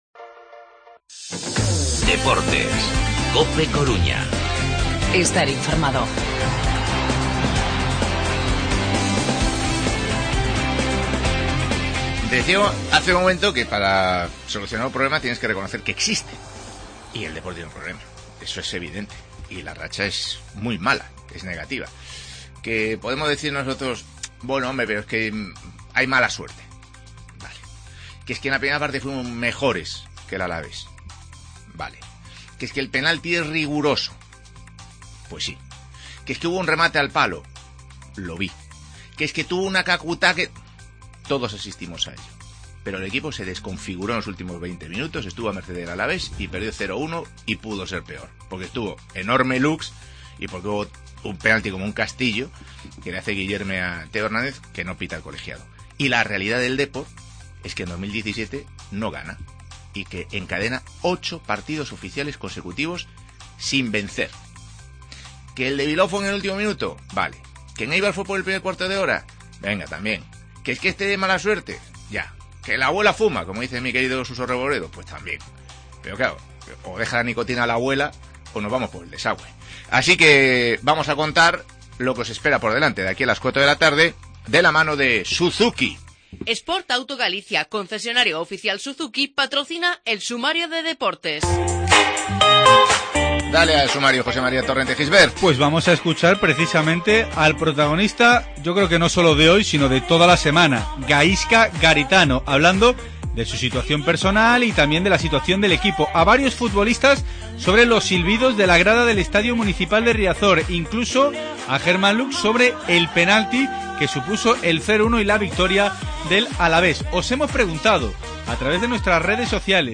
Escuchamos al técnico, a Juanfran y a Lux